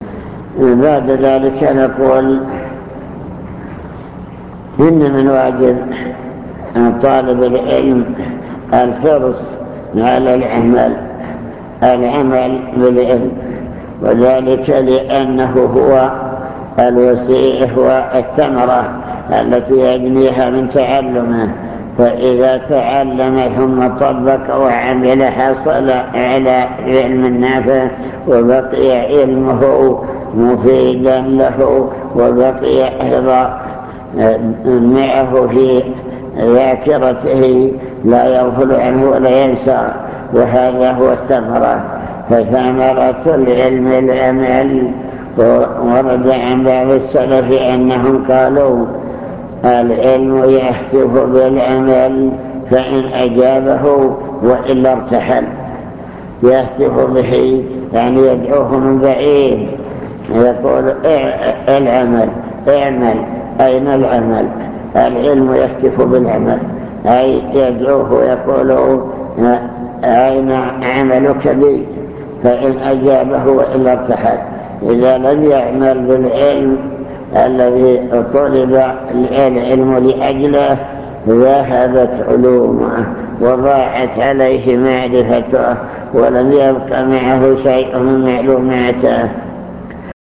المكتبة الصوتية  تسجيلات - لقاءات  كلمة حول طلب العلم